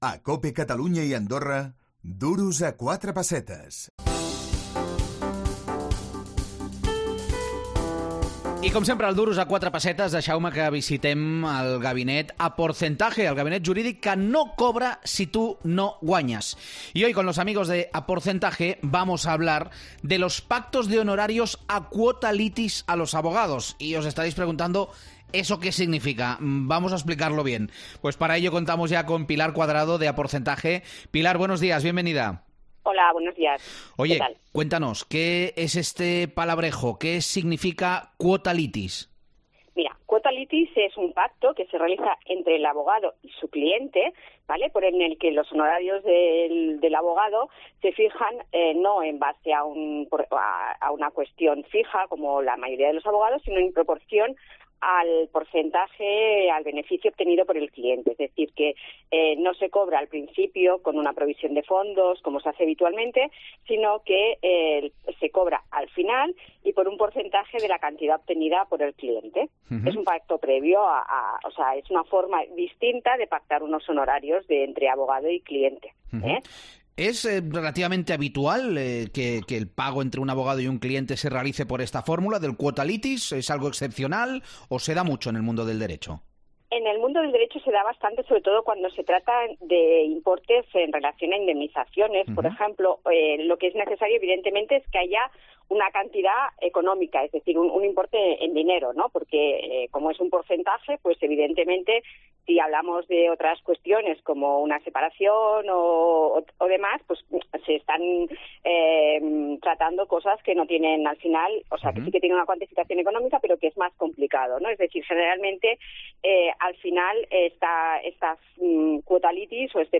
AUDIO: Els advocats de Aporcentaje ens ho expliquen